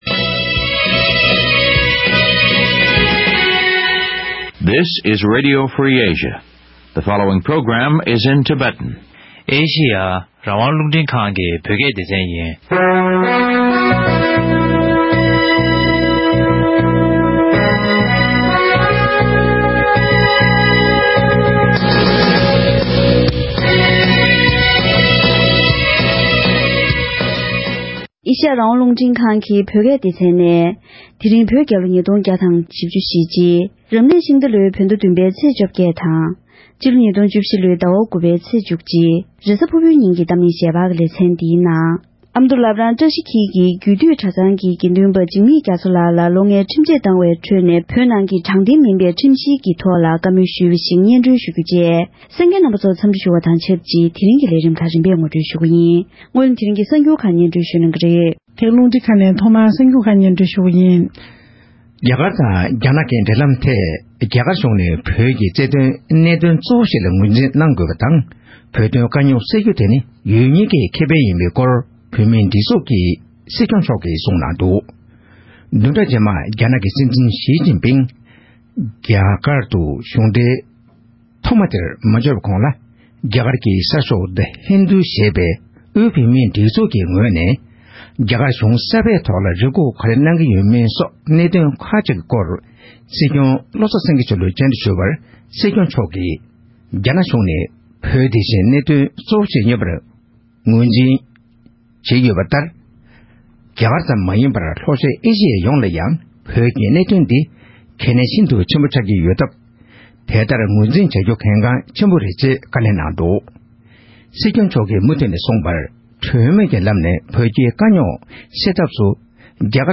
༄༅། །དེ་རིང་གི་གཏམ་གླེང་ཞལ་པར་ལེ་ཚན་འདིའི་ནང་།